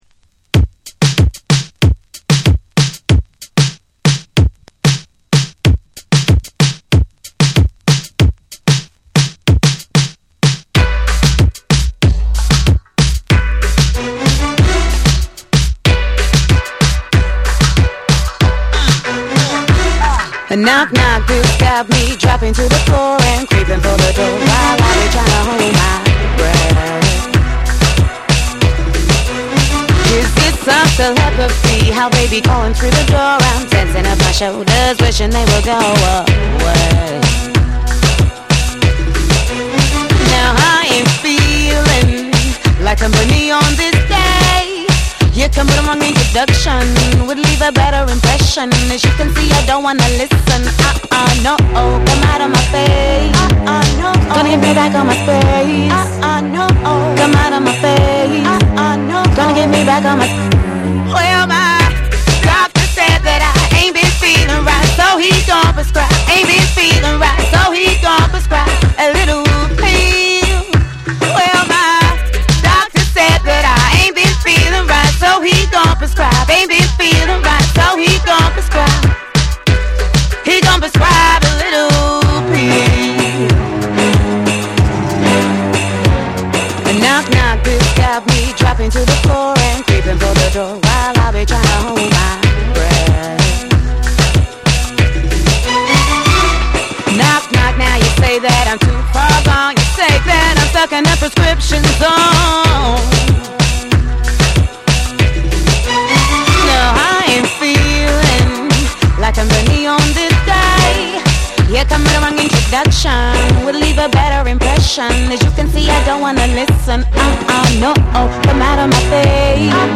オリジナルはグルーヴィーでフューチャリスティックなダンスホール・トラック。
BREAKBEATS / REGGAE & DUB